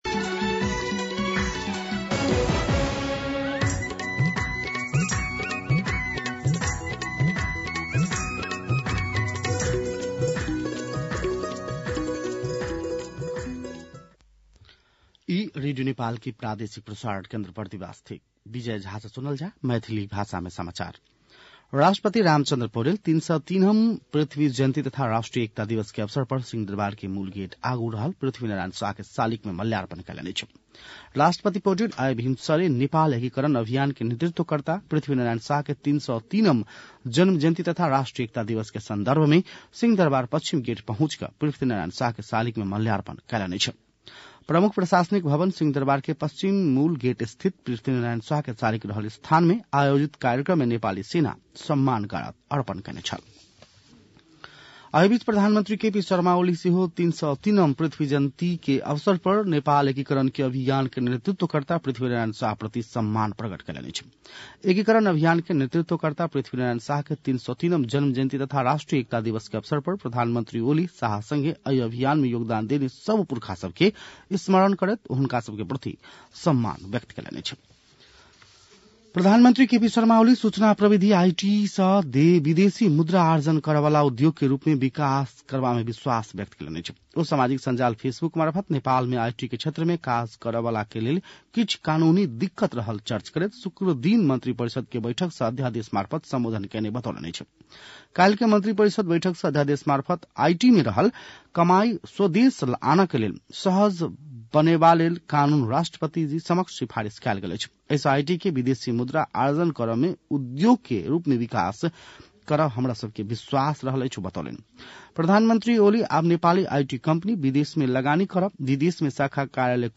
मैथिली भाषामा समाचार : २८ पुष , २०८१
Maithali-News-.mp3